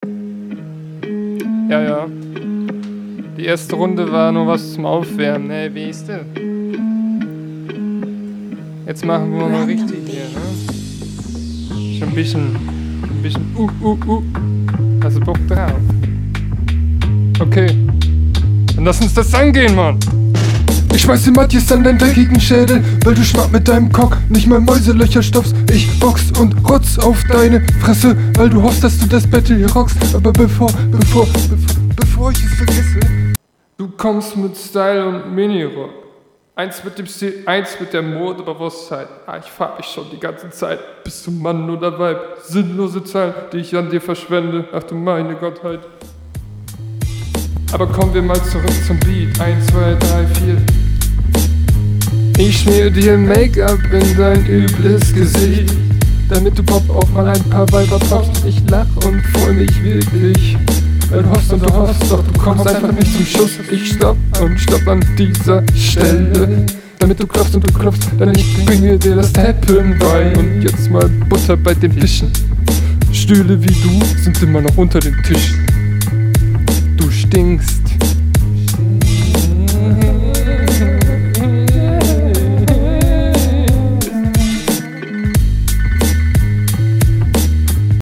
Geiler Beat.
Du stolperst aber gut über den Beat, der …